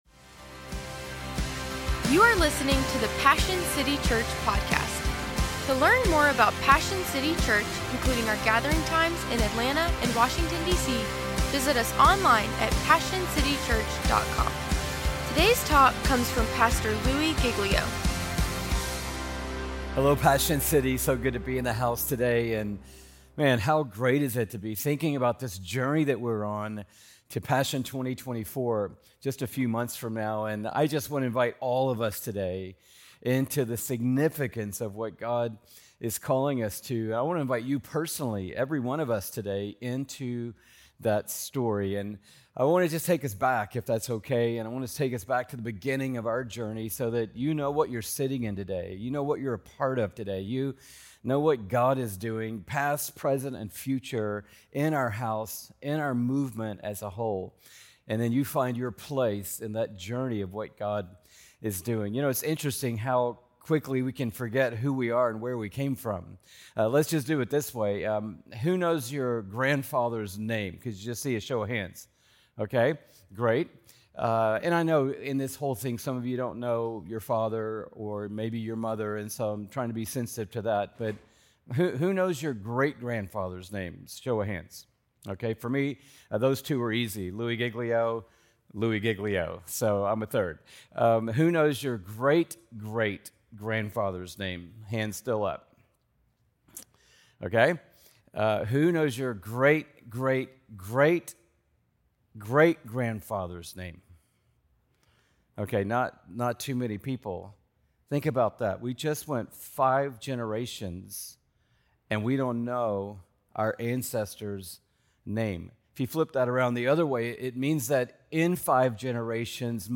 Christianity, Passion, Religion & Spirituality, Passionconferences, Messages, Louiegiglio, Sermons, Passioncitychurch, Church